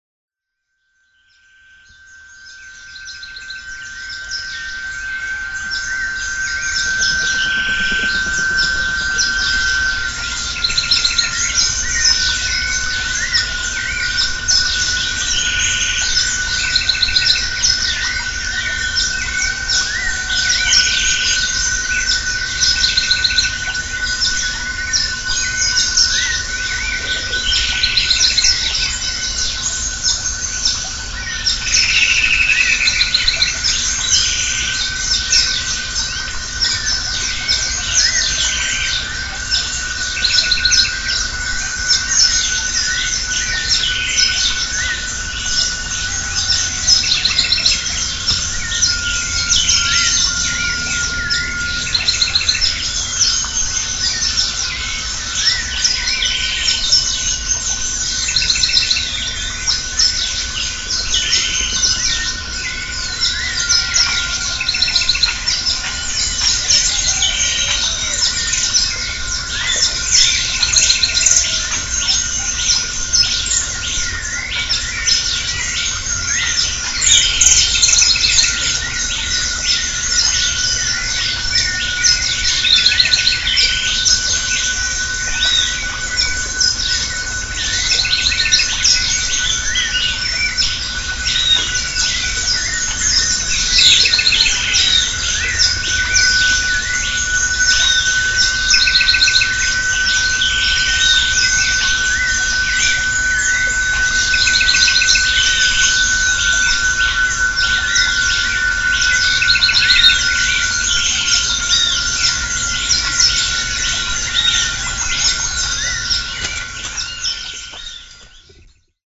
Geräuschdokumente der Natur.
Vögel, Frösche und eine Vielzahl im Verborgenen lebende Insekten sind die Musikanten.
Hier sehen Sie unsere kleinen Teichbiotope auf dem Gelände von CASA MARIA, wo die Aufnahmen der Frosch- und Vogelkonzerte gemacht wurden:
CASA MARIA: Vögel und Zikaden !
Konzert zum Sonnenaufgang.